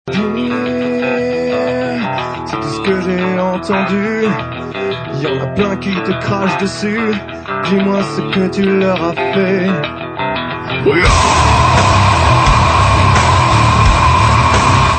hardcore